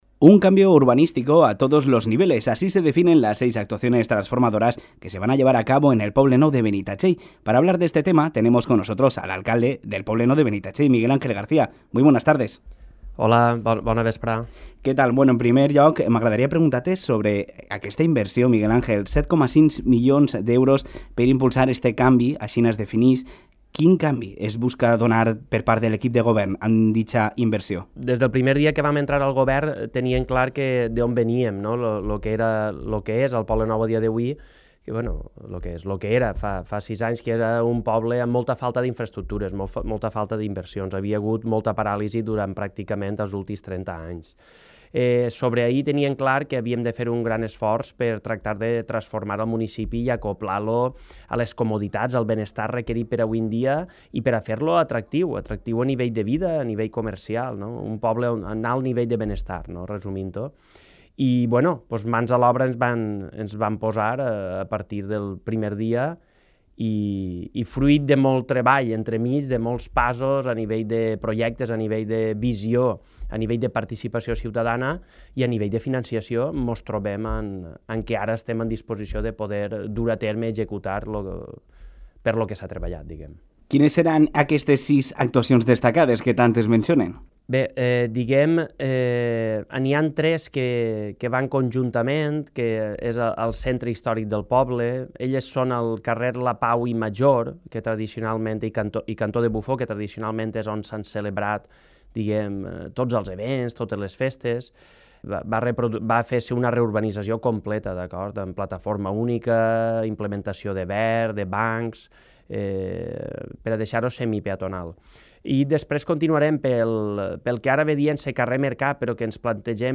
En l’Informatiu de Ràdio Litoral del 27 de novembre, hem entrevistat a Miguel Ángel García, alcalde del Poble Nou de Benitatxell per a conéixer de prop les pròximes actuacions que es duran a terme durant els pròxims mesos en el municipi.
ENTREVISTA-MIGUEL-ANGEL-BENITATXELL-NOVIEMBRE.mp3